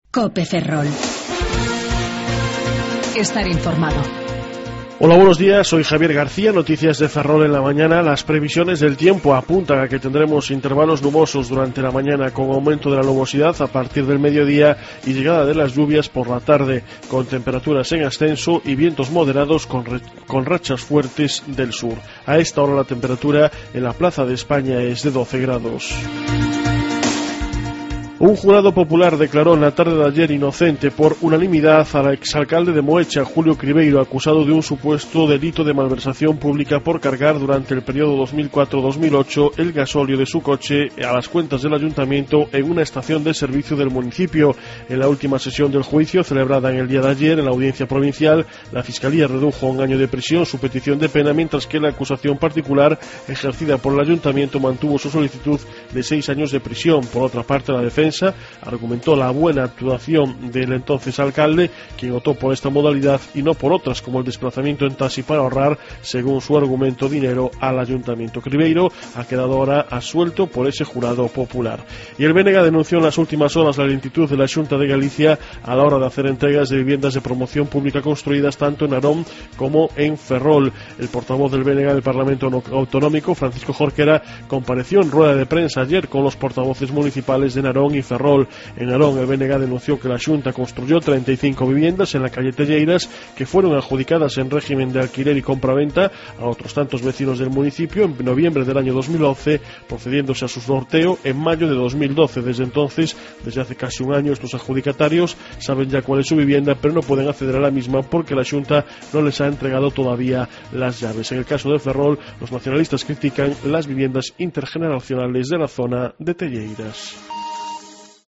08:28 Informativo La Mañana